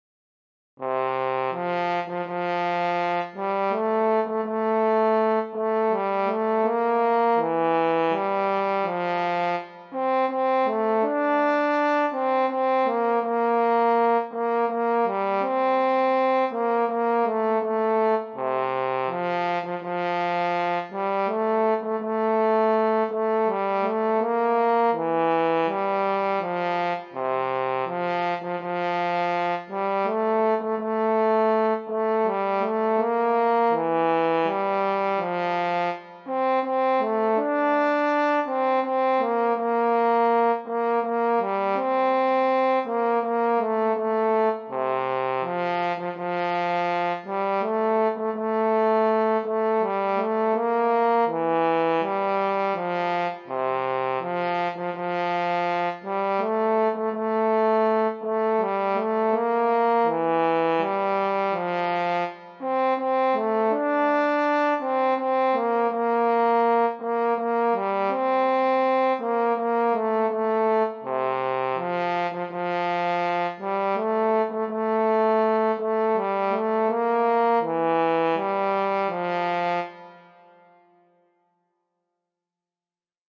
für Horn solo